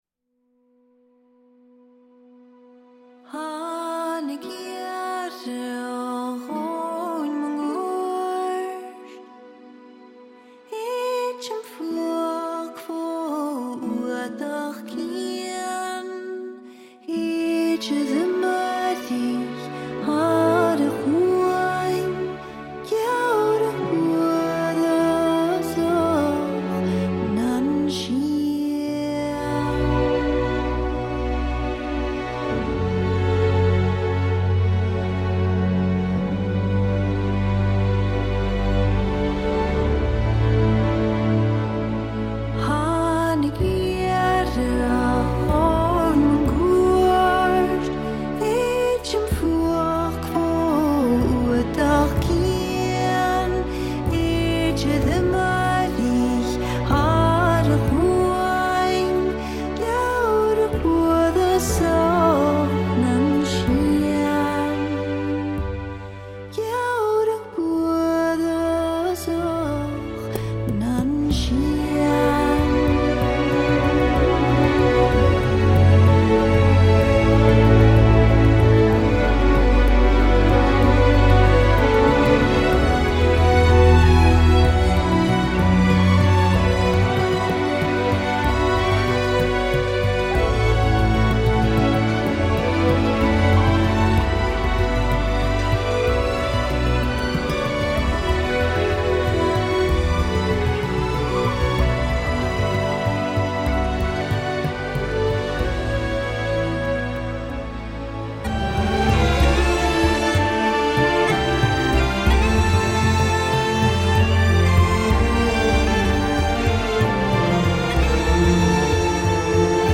سبک موسیقی (Genre) موسیقی متن, موسیقی سریال